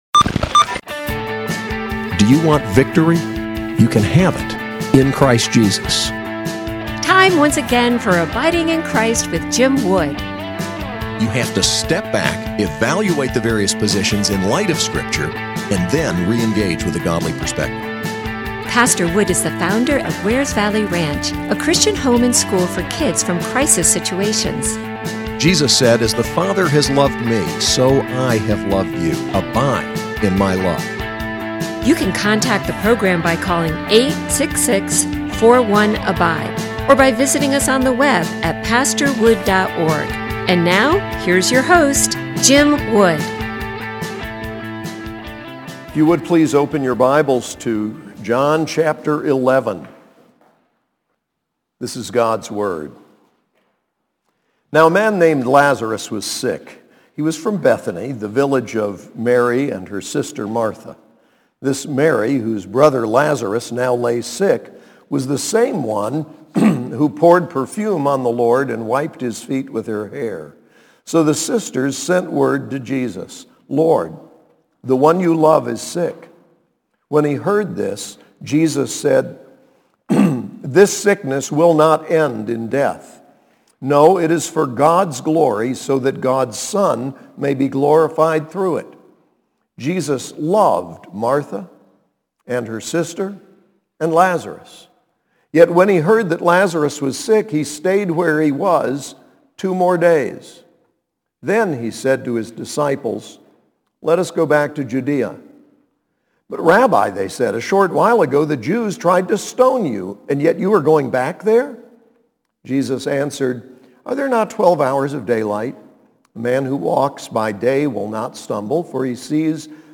SAS Chapel: John 11